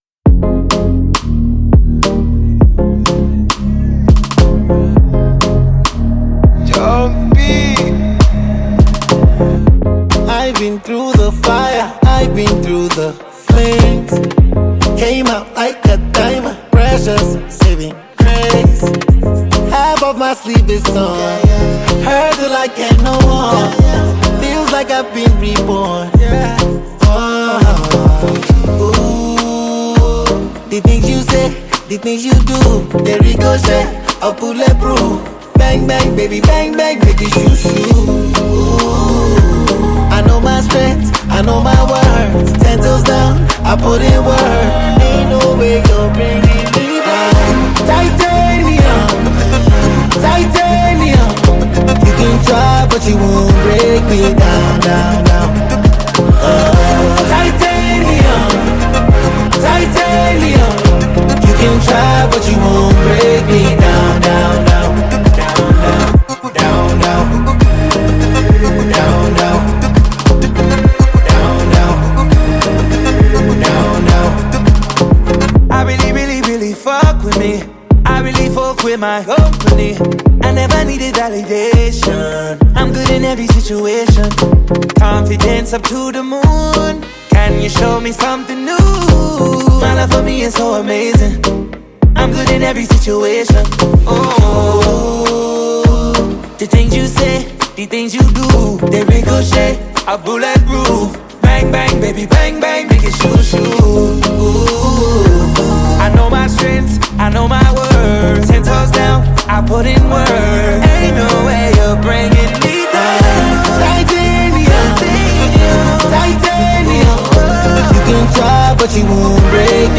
” a mesmerizing and magnificent melody.